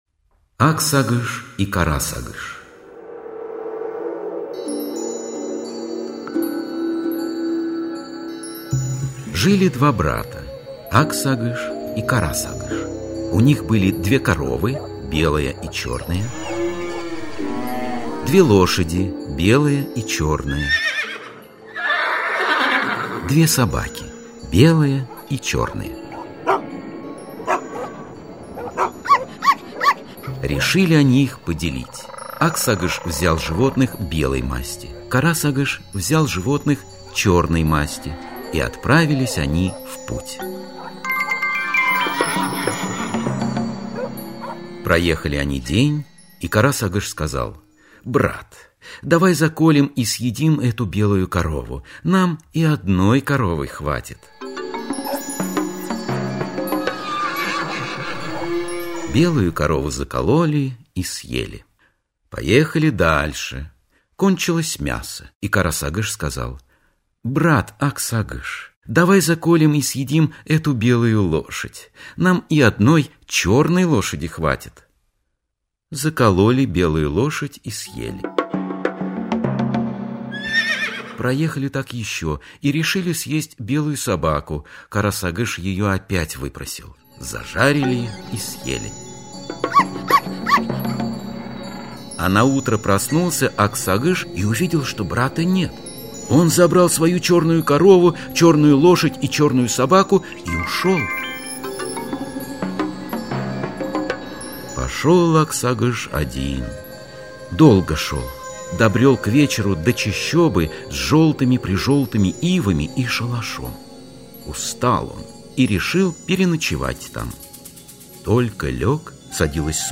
Ак-Сагыш и Кара-Сагыш - алтайская аудиосказка - слушать онлайн